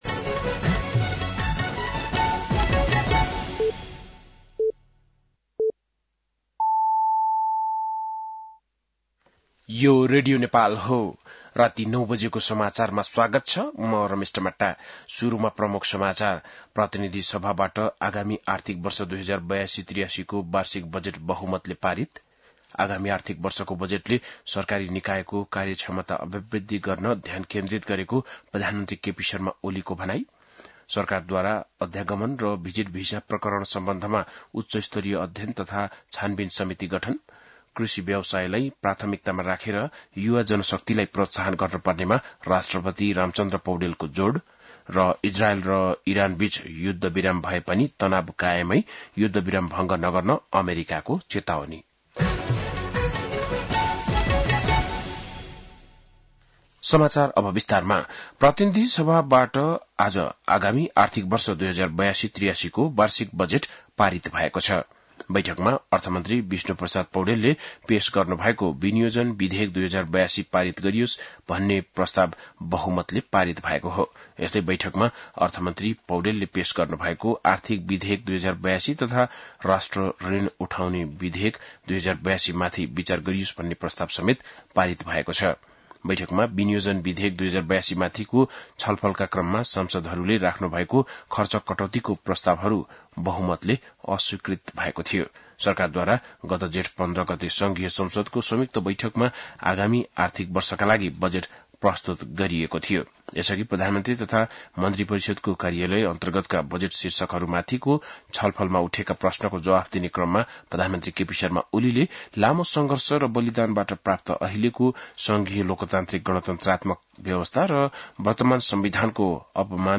An online outlet of Nepal's national radio broadcaster
बेलुकी ९ बजेको नेपाली समाचार : १० असार , २०८२